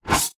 Select Scifi Tab 4.wav